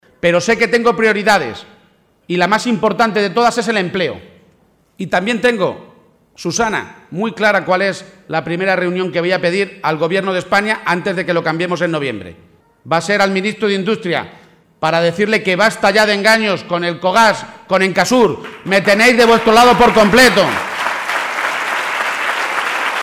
Lo ha hecho en un acto ante más de 500 personas, en el que ha estado por la Presidenta de la Junta de Andalucía, Susana Díaz.